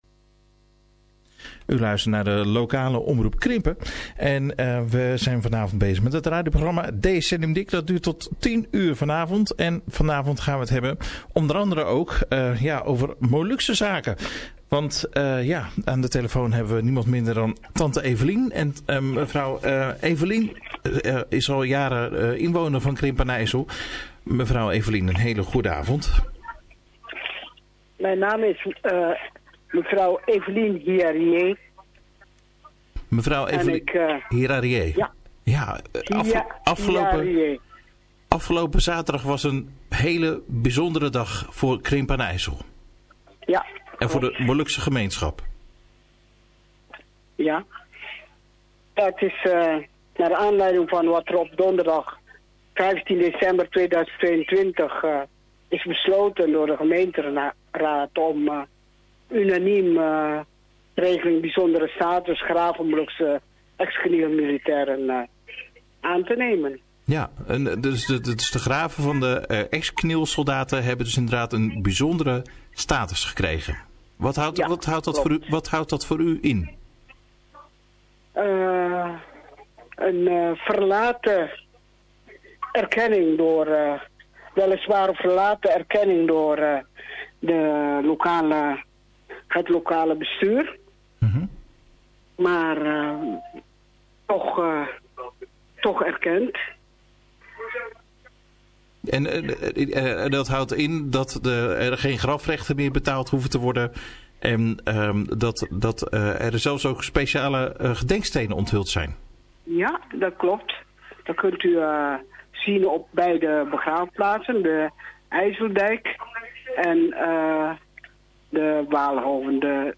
Interview door Lokale Omroep Krimpen met KN Wattilete - Republik Maluku Selatan